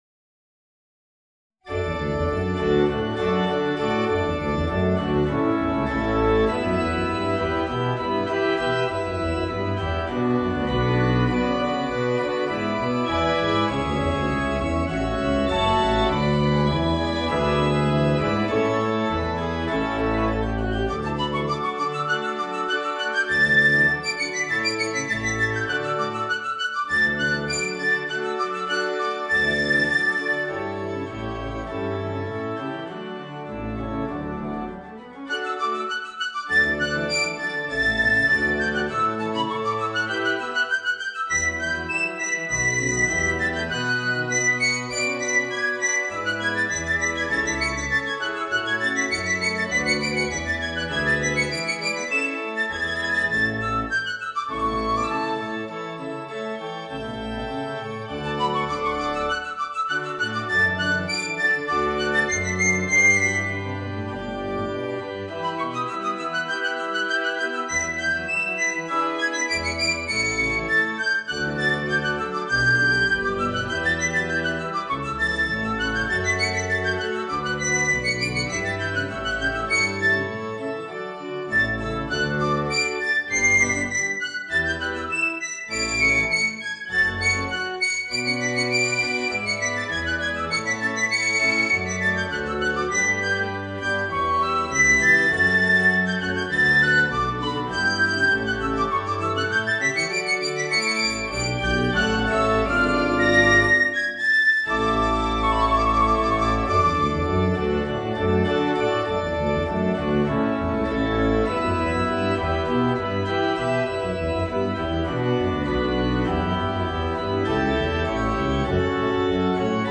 Voicing: Piccolo and Organ